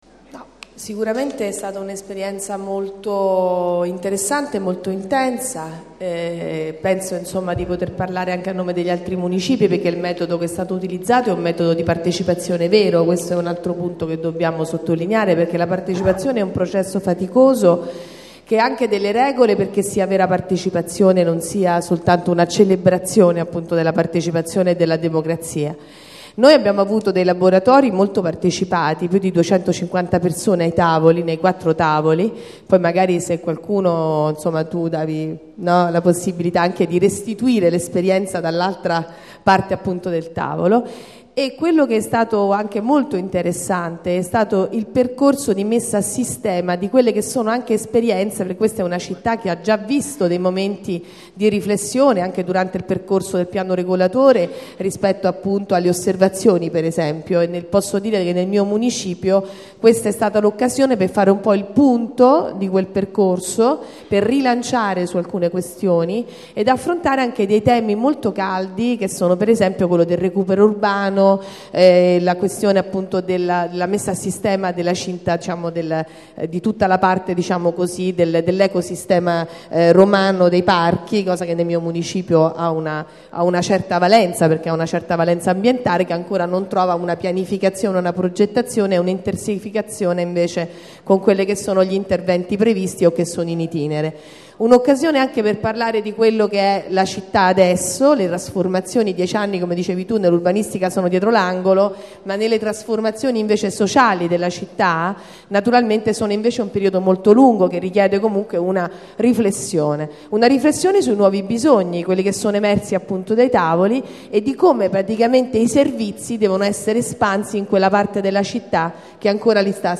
Registrazione integrale dell'incontro svoltosi il 19 dicembre 2014 presso la Casa della Città, in P.za Da Verrazzano, 7.
Cristina Maltese   Cristina Maltese, Presidente Municipio XII